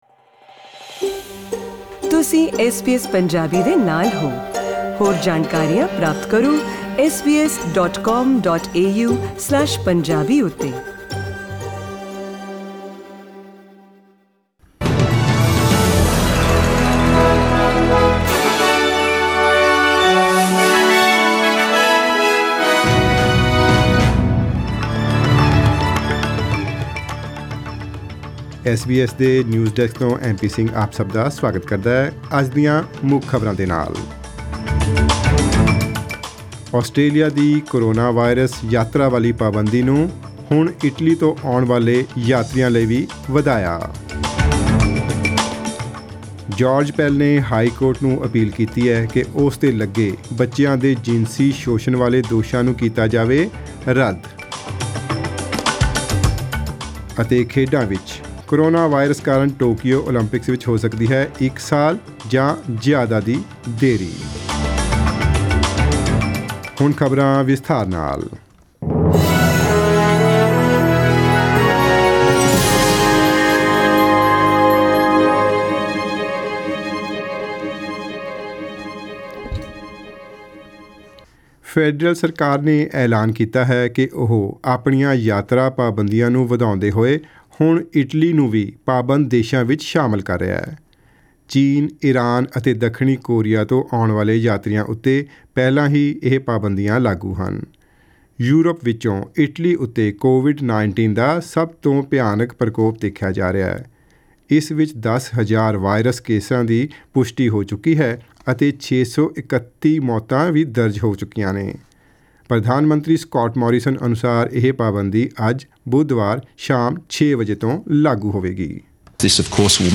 Australian News in Punjabi: 11 March 2020